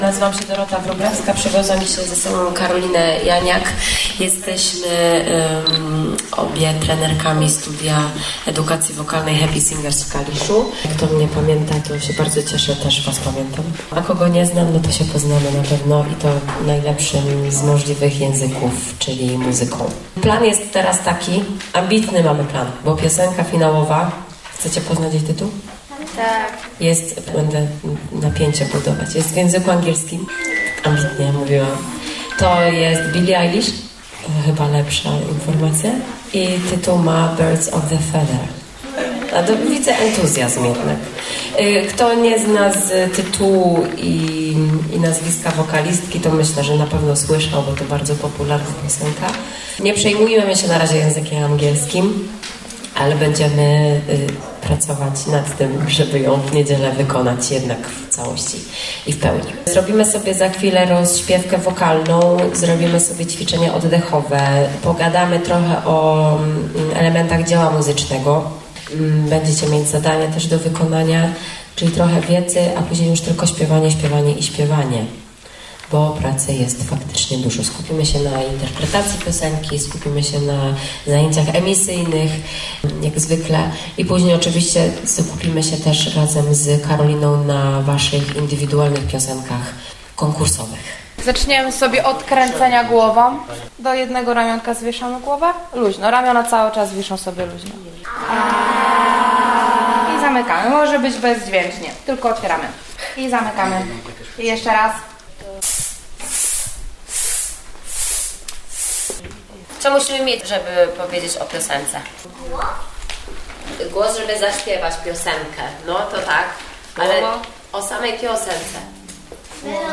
Trzynastego czerwca w Gminnym Ośrodku Kultury w Brzeźnie rozpoczęły się warsztaty wokalne dla finalistów piątego Festiwalu Dobrej Piosenki.